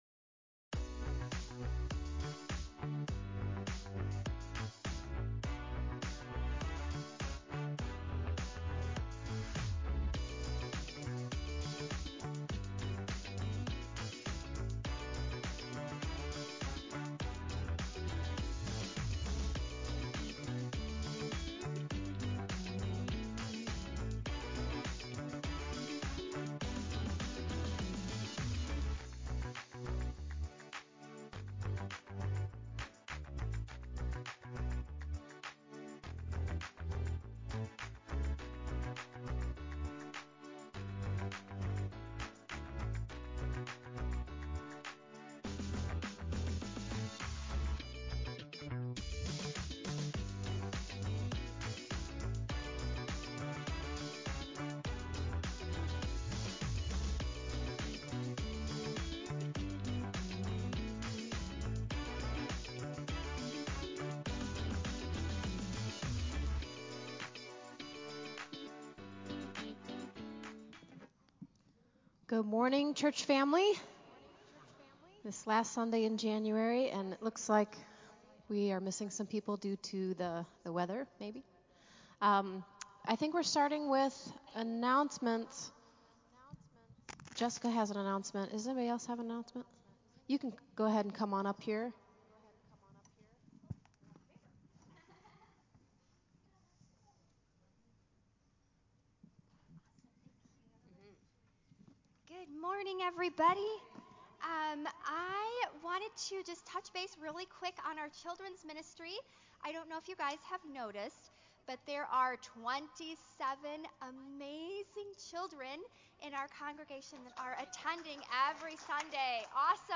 Another fantastic session of worship from our talented team.
Praise Worship